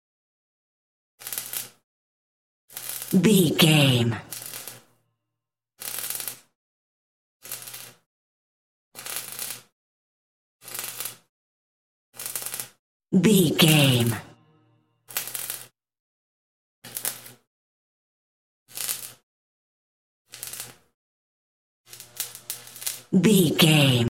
Welder short points
Sound Effects
industrial
electroshock